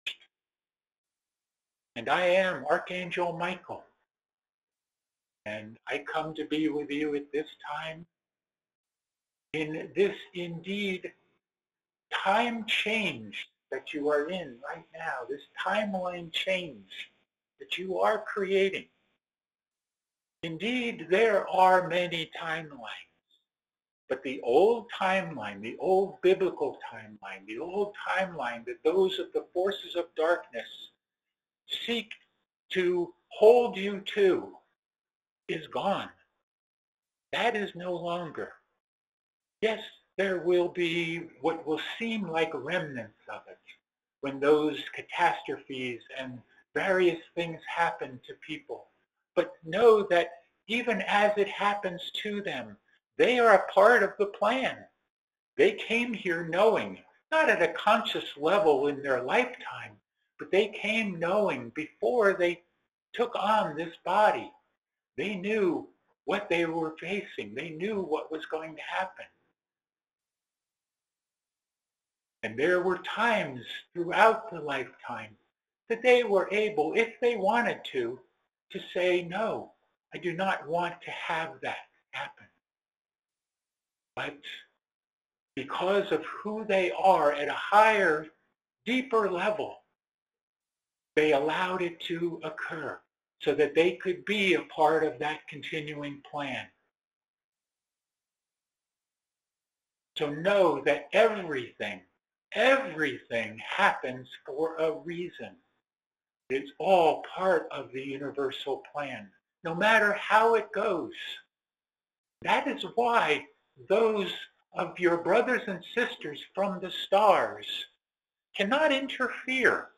These messages were given during our Ancient Awakenings weekly Sunday conference call in Payson, AZ on February 2, 2025.